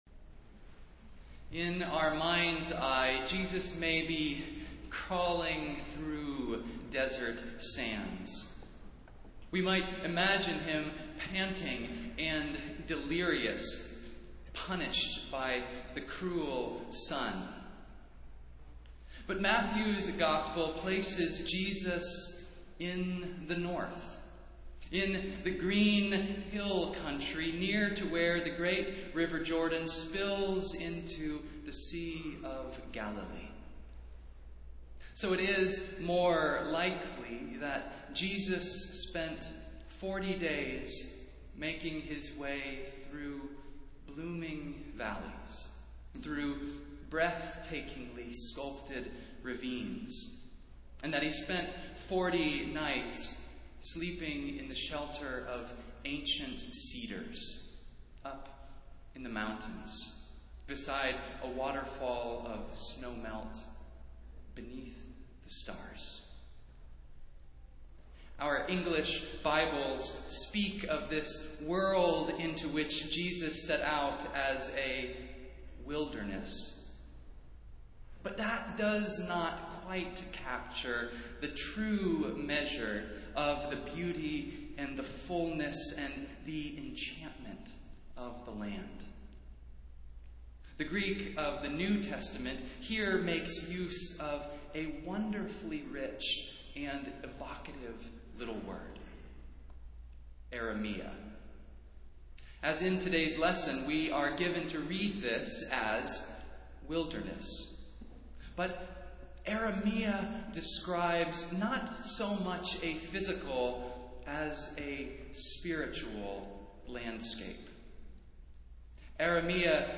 Festival Worship - Fourth Sunday in Lent